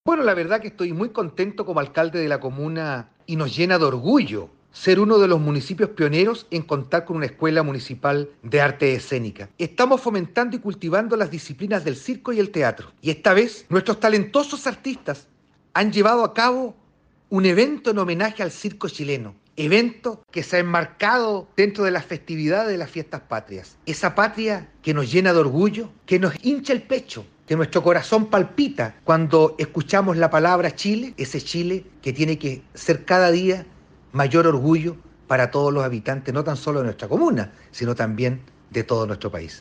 CUNA-ALCALDE-JEV-EMAEC.mp3